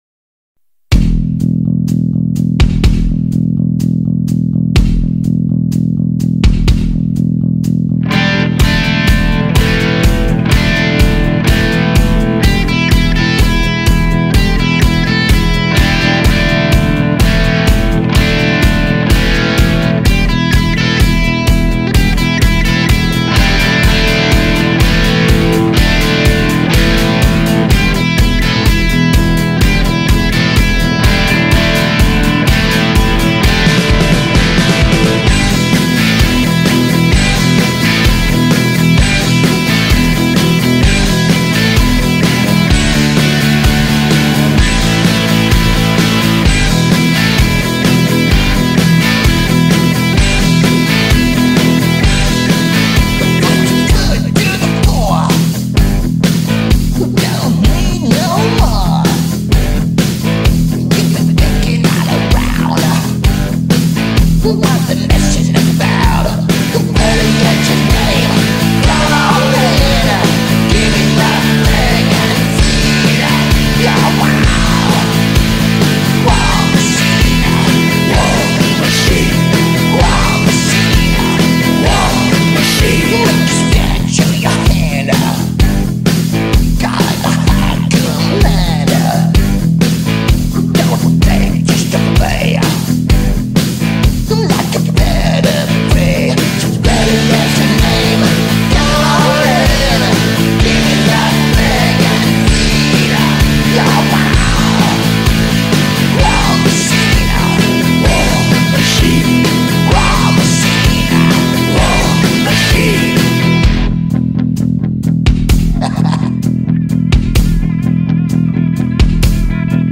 The mixing is very bad.